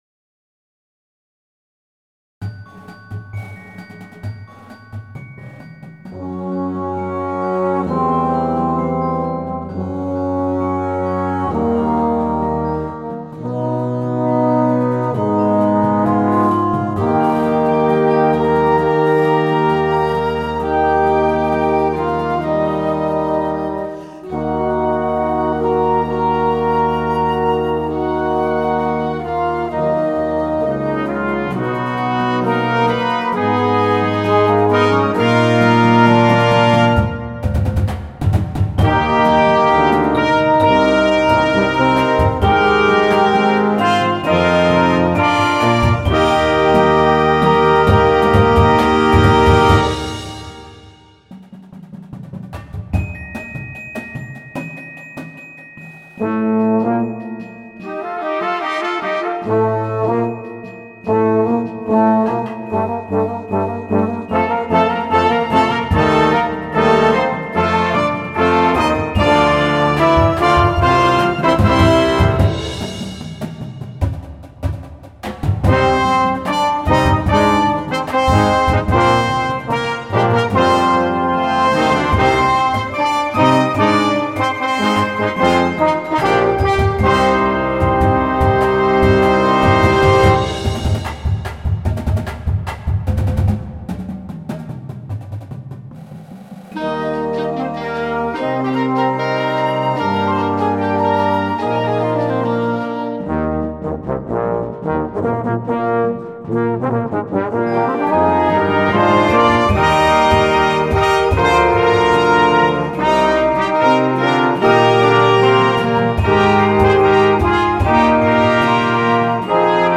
Full Show (short version) with Basic Percussion Only